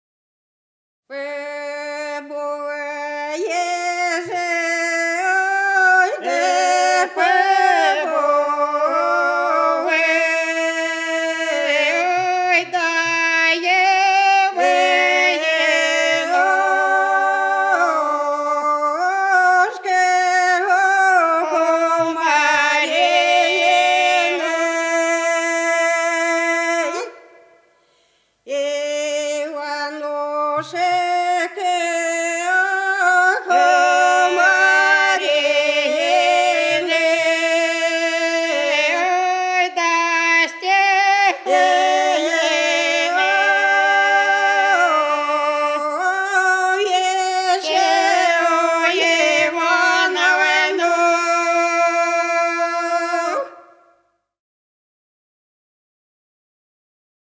Голоса уходящего века (село Фощеватово) Побывай, Иванушка, у Марины (покосная, величание)